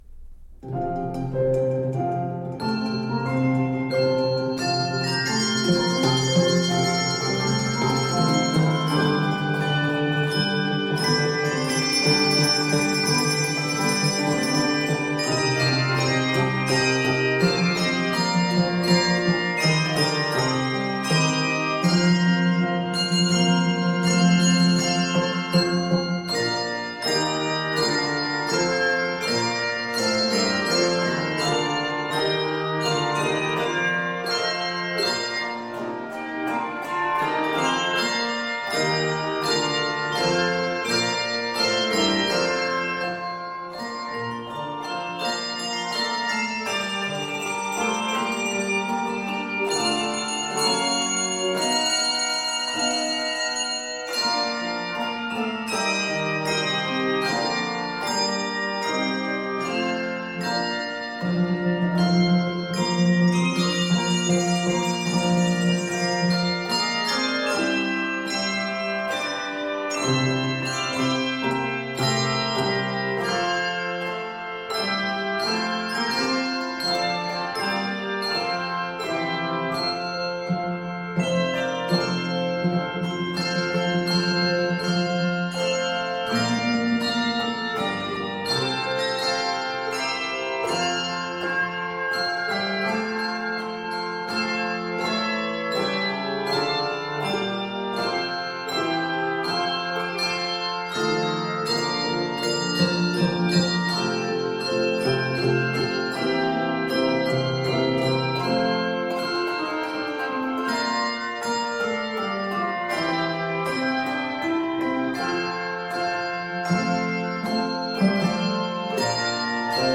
Bold and striking